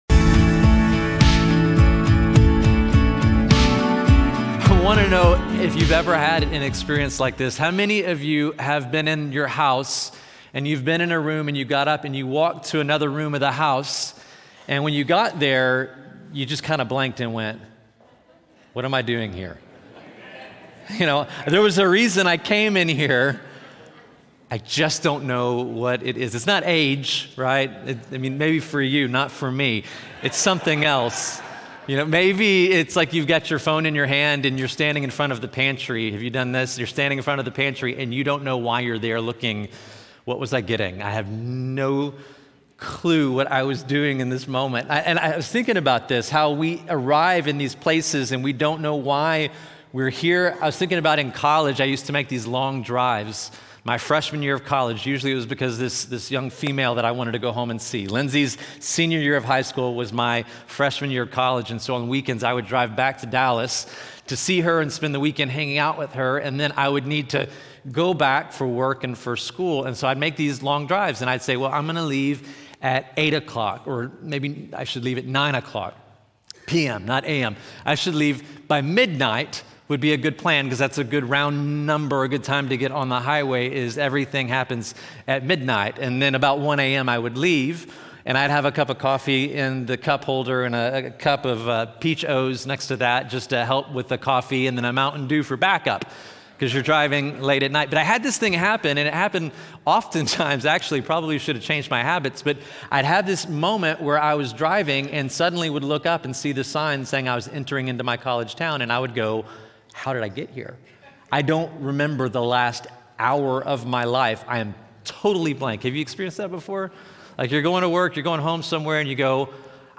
This sermon uses the parable of the Prodigal Son to show how people drift away from God—not through sudden decisions, but gradual deception that convinces them something other than God can satisfy. It emphasizes that grace dismantles this deception: when we turn back, God doesn’t wait in judgment but runs toward us with compassion, restoring our dignity, identity, and joy. Finally, it warns that both rebellion and self-righteousness can keep us far from the Father’s heart, reminding us that true faith is found not in earning His favor, but in simply being with Him.